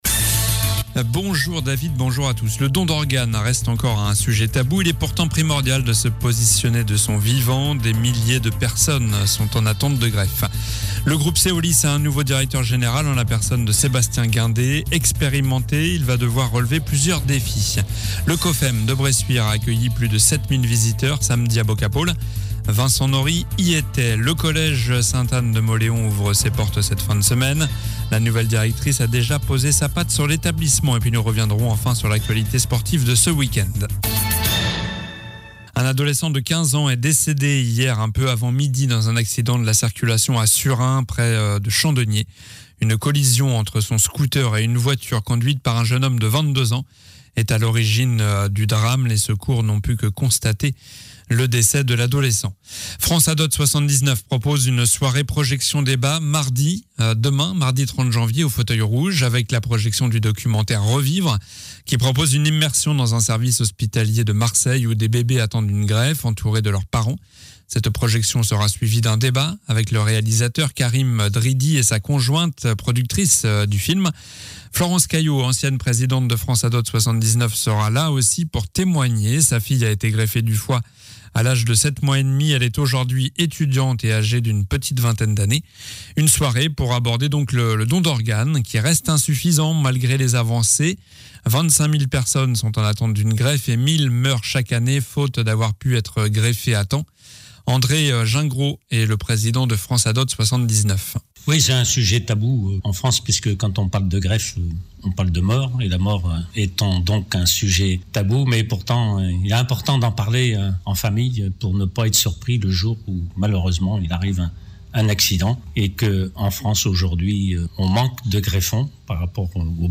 JOURNAL DU LUNDI 29 JANVIER ( MIDI )